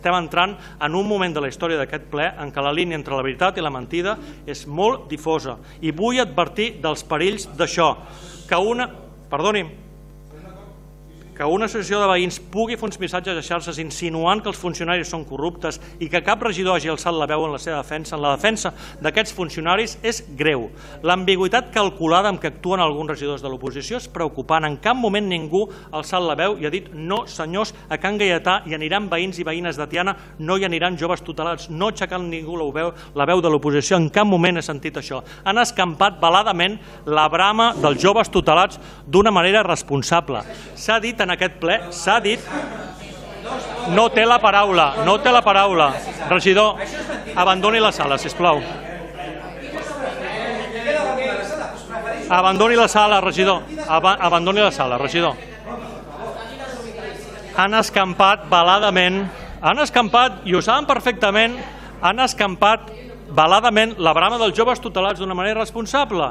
El ple d’aquest dimarts 4 de març va ser llarg i intens, amb un intercanvi de retrets entre regidors, crítiques del públic i fins i tot la marxa del ple del portaveu dels Socialistes de Tiana quan l’alcalde va acusar l’oposició de no defensar la institució i de permetre que s’escampessin discursos que posaven en dubte la professionalitat dels treballadors municipals i de l’IMPSOL. Miquel Santiago aclareix en una nota de premsa que “tot i que en les imatges només se sent a l’alcalde dir que abandoni la sala, jo ja havia pres la decisió de marxar abans que ell em fes aquesta indicació”, al·legant que no va ser expulsat. “Quan em vaig aixecar li estava explicant que marxava com a acte de protesta a causa de les greus insinuacions que estava fent, però no es va sentir el que jo li deia perquè el micròfon el tenia l’alcalde”, diu.